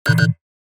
Horror, Survival, Game Menu, Ui Error Sound Effect Download | Gfx Sounds
Horror-survival-game-menu-ui-error.mp3